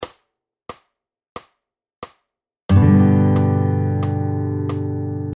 ※エクササイズの際のコードストロークは全てダウンストロークで演奏しましょう。
EX３　Gコードで全音符で弾いてみよう